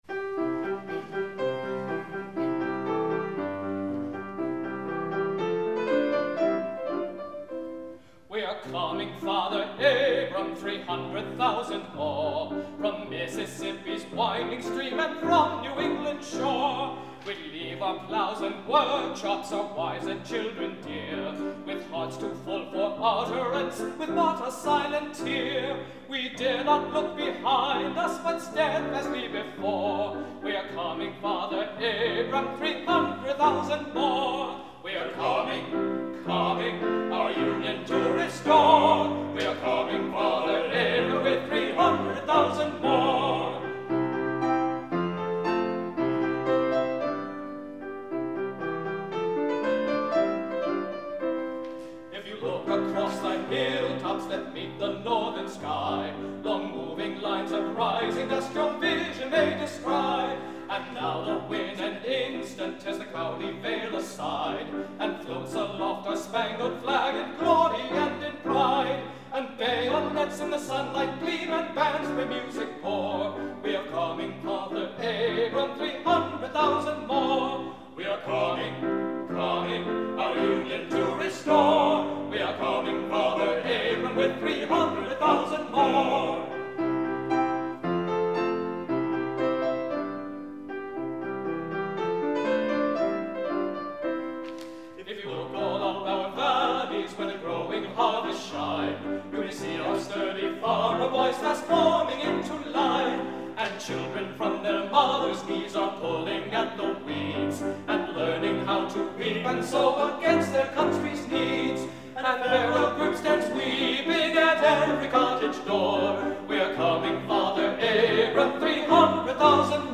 As part of the programs-in-exile, Songfellows, a quartet which grew out of the Metropolitan Opera Chorus, was asked to perform Civil War Songs songs on a program titled Songs of an Emerging Nation.
The Library presented this program at the Great Hall on June 5, 1999.
Civil War Songs
"We Are Coming, Father Abraam, 300,000 More," by Stephen C. Foster, 1862